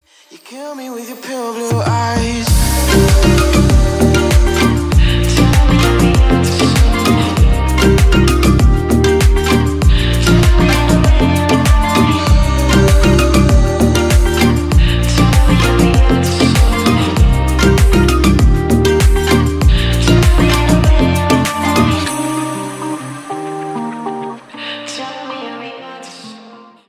• Качество: 160, Stereo
атмосферные
Electronic
Midtempo
tropical house
Chill